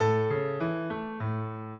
piano
minuet7-12.wav